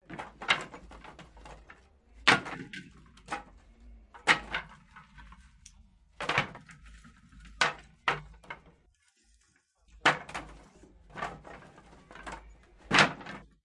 描述：由木材对金属的刮擦而形成。
Tag: MTC500-M002-S1 木材 MTC500-M002-S13 敲击